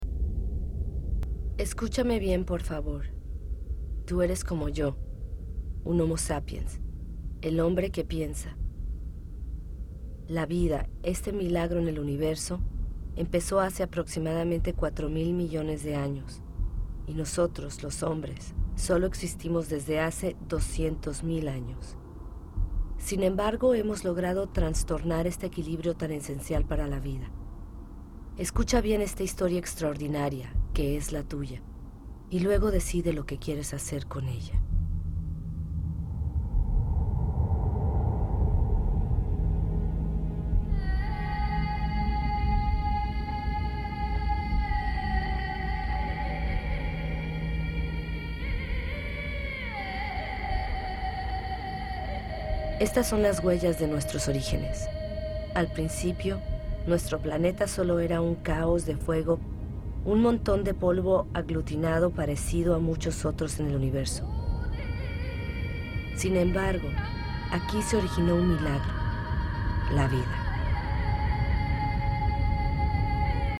Home, narrado por Juan Echanove, tuvo su estreno mundial el pasado 5 de junio en todos los soportes de imagen: Cine, DVD, Internet..¿el fin?